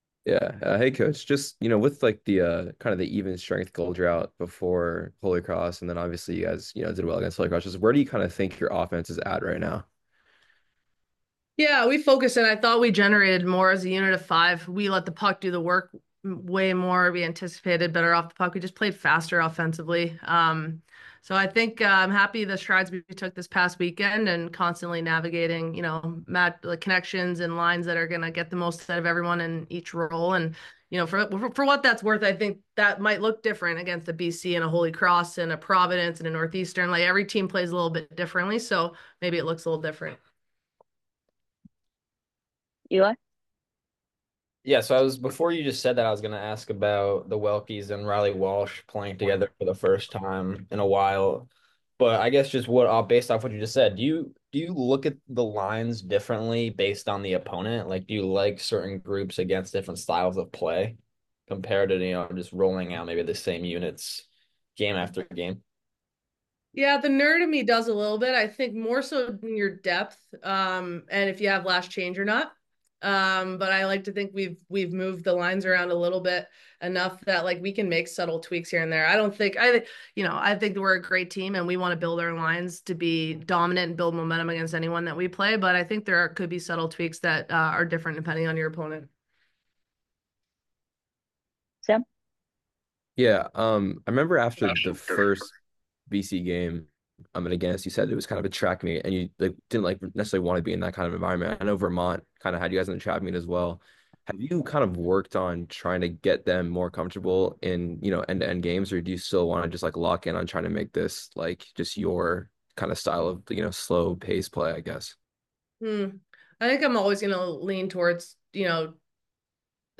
Media Call